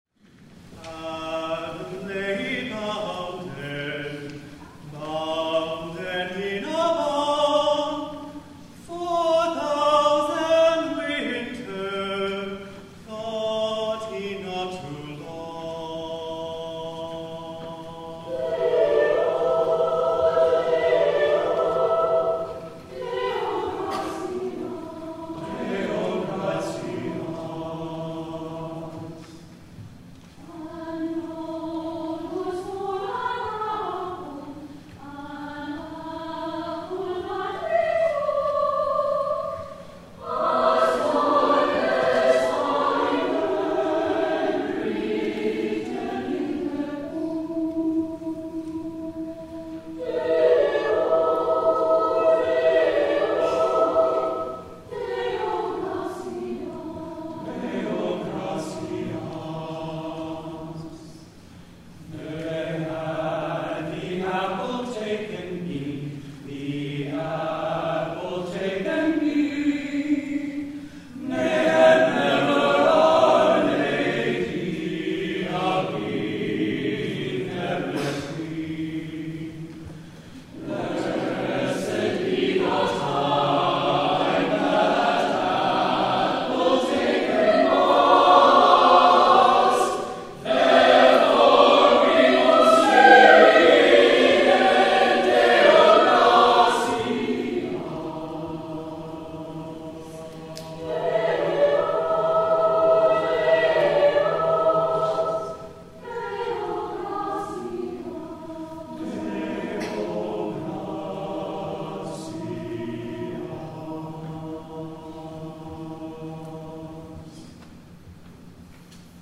8 P.M. WORSHIP
THE CAROL